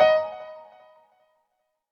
piano_last164.ogg